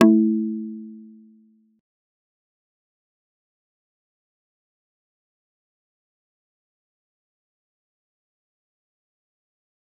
G_Kalimba-A3-pp.wav